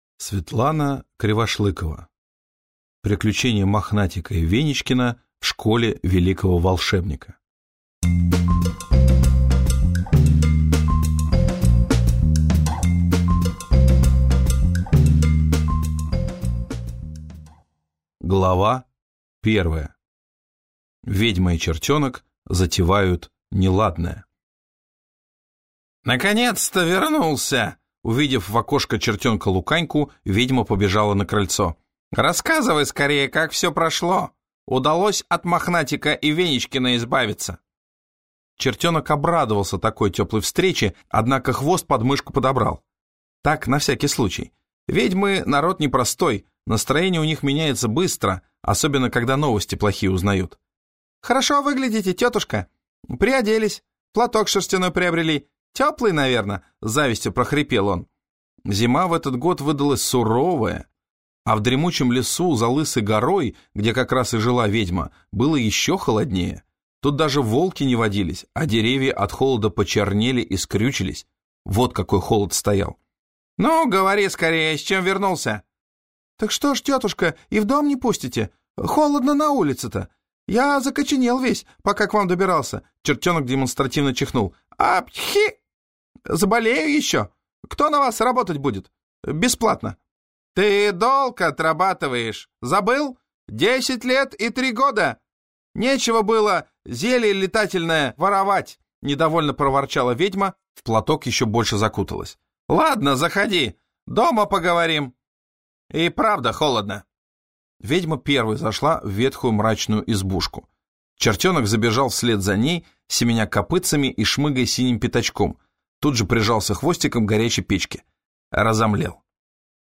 Аудиокнига Приключения Мохнатика и Веничкина в школе Великого Волшебника | Библиотека аудиокниг